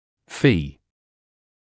[fiː] [фиː] вознаграждение; гонорар